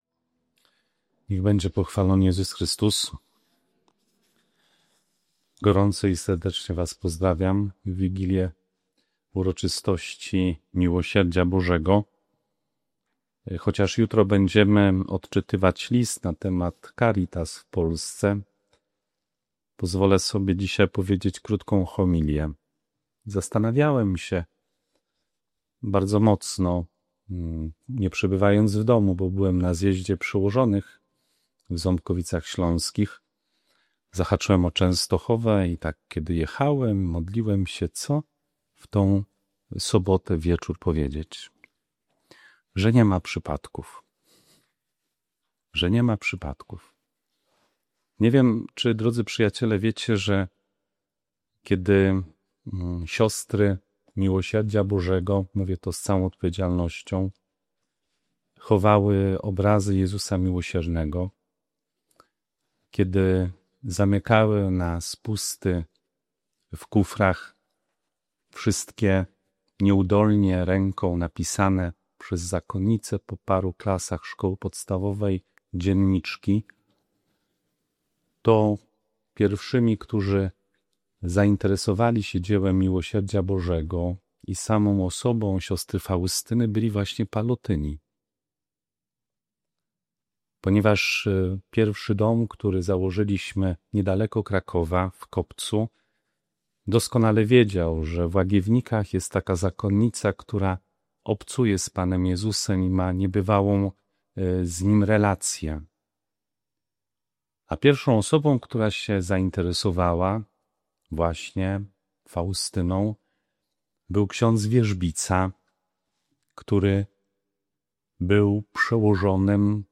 Transkrypcja
Homilia w wigilię Uroczystości Miłosierdzia Bożego podkreśla szczególną rolę pallotynów w szerzeniu kultu Jezusa Miłosiernego i w promowaniu duchowego dziedzictwa św. Faustyny. Głównym przesłaniem jest wezwanie do życia miłosierdziem na wzór Boga: nie tylko przez pomoc materialną, lecz przez dar z siebie, obecność, dobroć i przebaczenie.